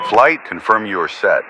Added .ogg files for new radio messages
Radio-playerWingmanReportStatus1.ogg